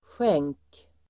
Uttal: [sjeng:k]